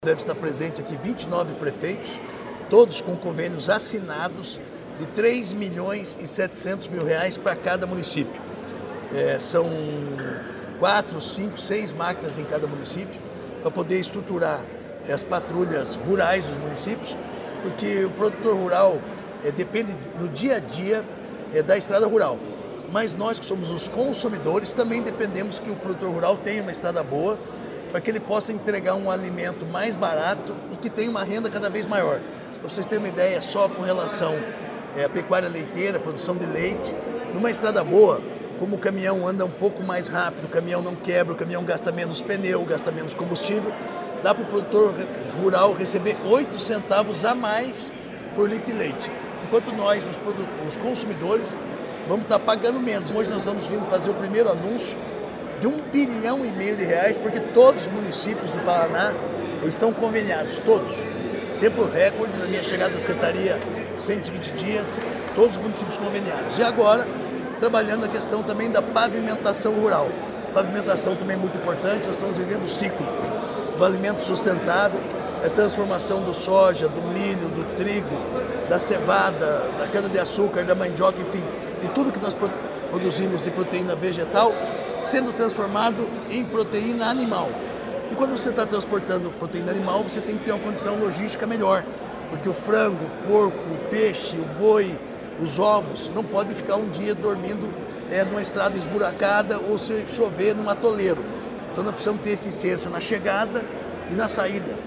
Sonora do secretário da Agricultura e do Abastecimento, Márcio Nunes, sobre o Plano Estadual de Logística e Transportes